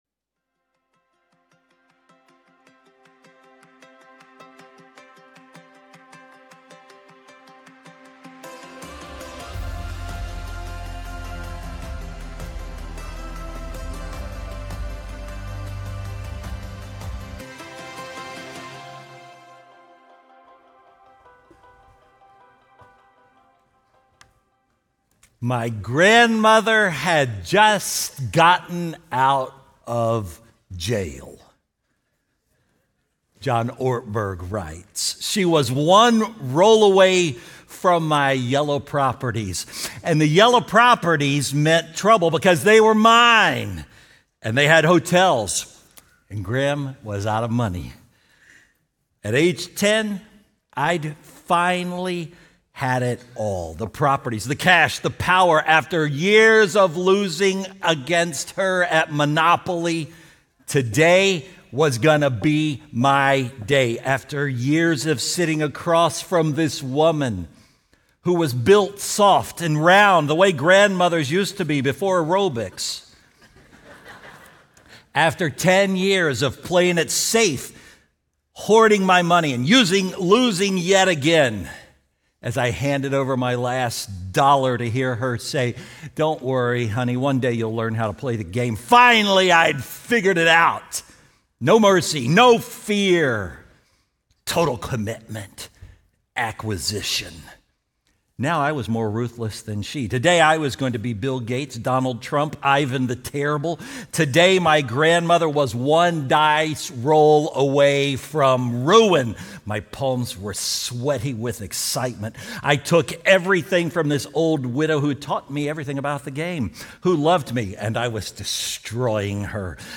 Faithbridge Sermons It All Goes Back in the Box Nov 02 2025 | 00:41:10 1x 00:00 / 00:41:10 Subscribe Share Apple Podcasts Spotify Overcast RSS Feed Share Link Embed